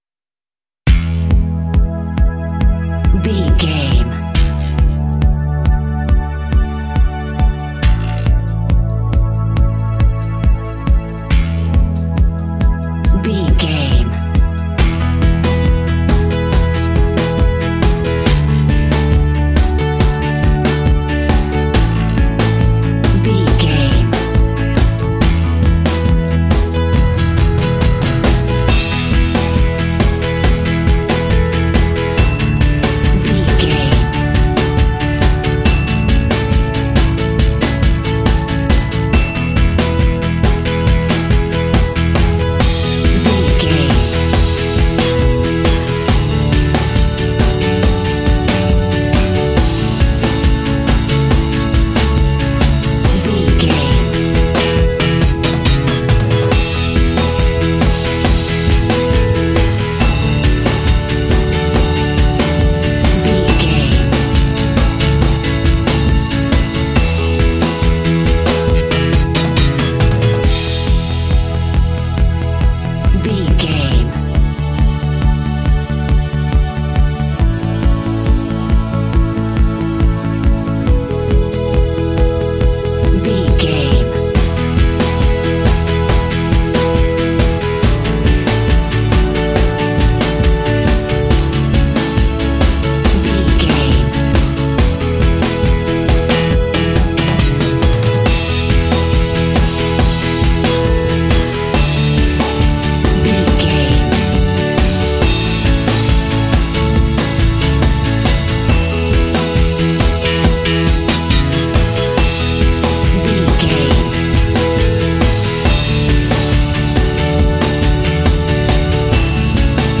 Uplifting
Ionian/Major
driving
bouncy
happy
groovy
bright
electric guitar
bass guitar
strings
pop
alternative rock
indie